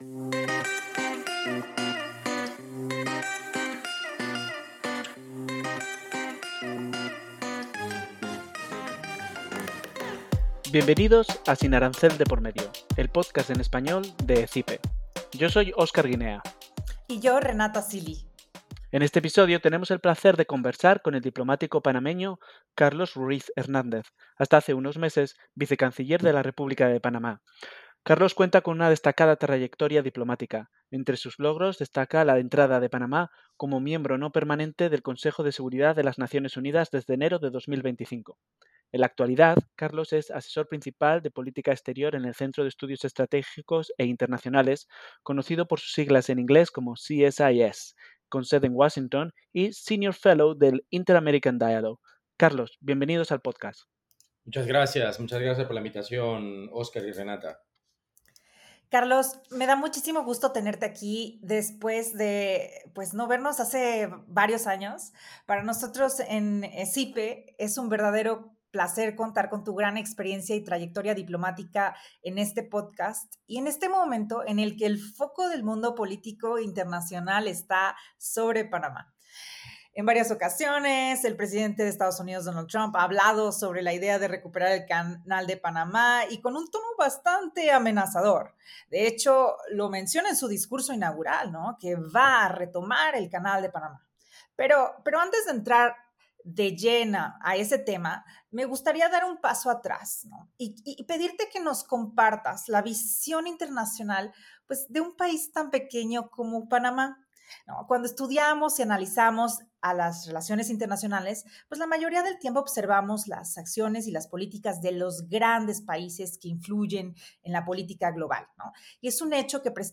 El episodio 4 del pódcast Sin Arancel de por Medio presenta una conversación con Carlos Ruiz-Hernández, ex vicecanciller de Panamá y actual asesor en CSIS y el Inter-American Dialogue.